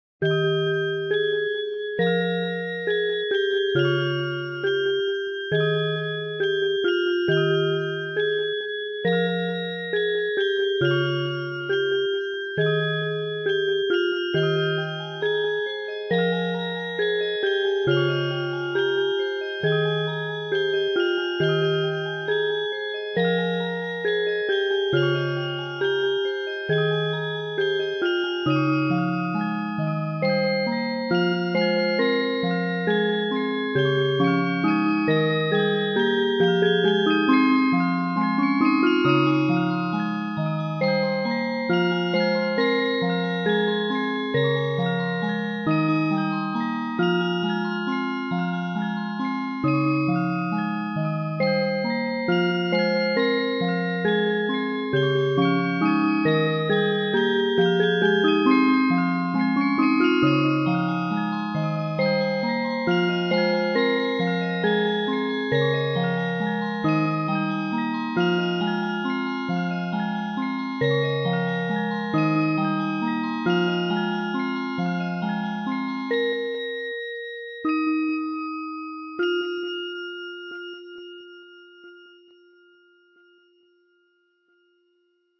BGM
Speed 80%